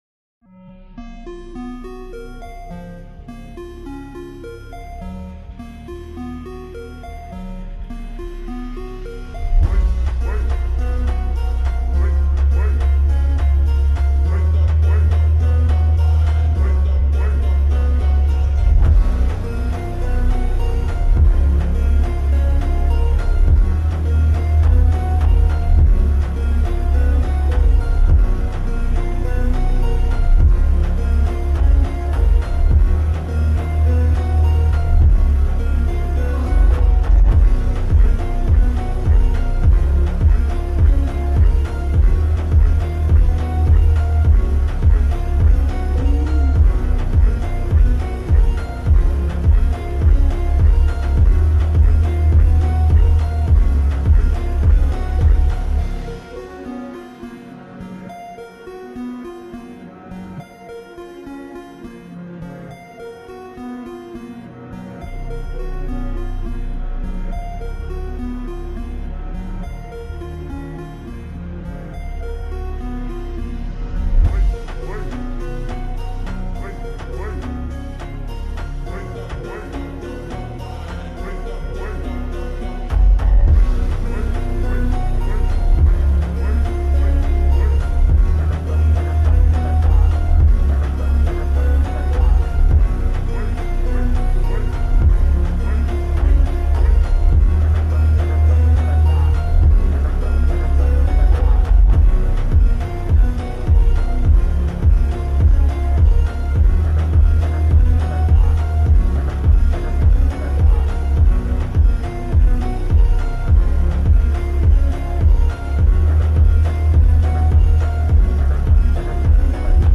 Slowed + Reverb + Eco + Bass Boosted × Epicenter Bass